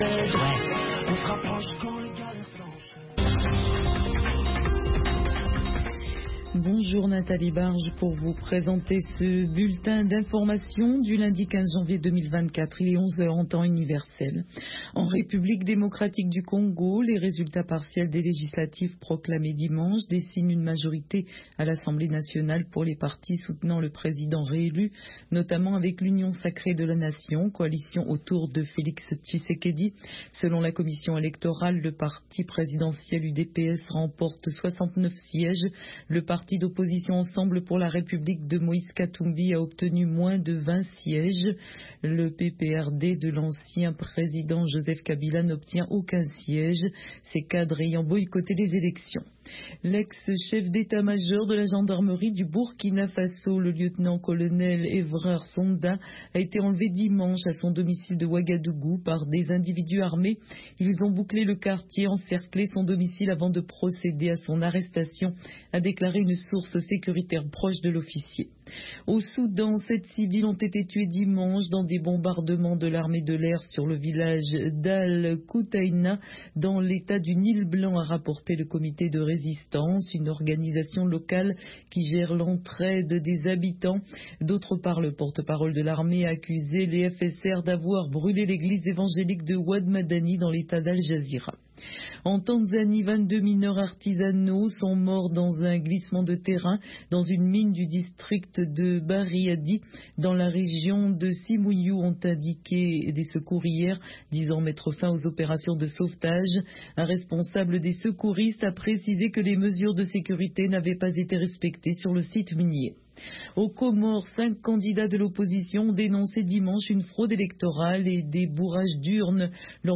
Bulletin d’information de 15 heures
Bienvenu dans ce bulletin d’information de VOA Afrique.